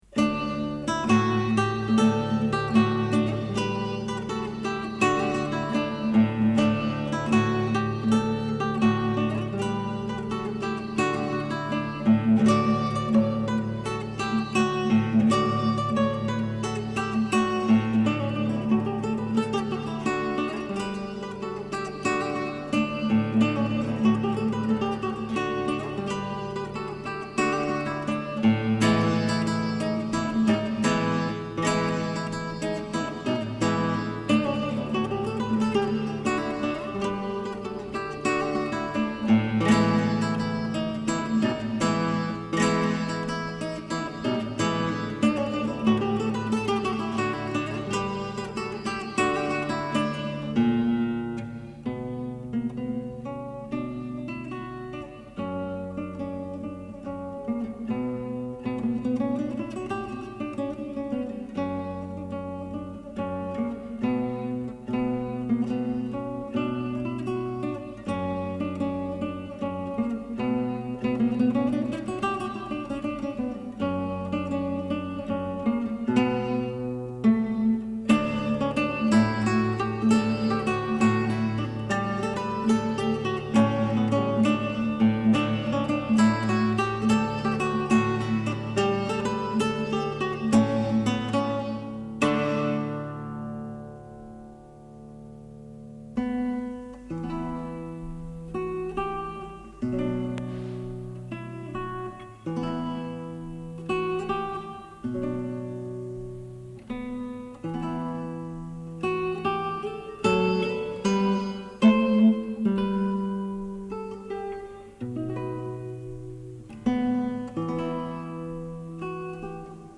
Classical guitar pieces